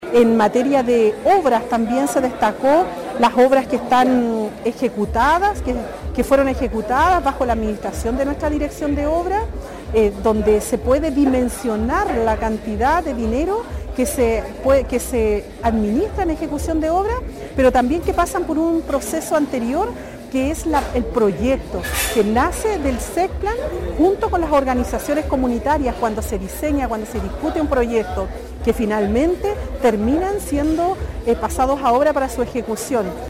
Alcaldesa rinde Cuenta Pública gestión 2024